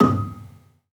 Gambang-D#5-f.wav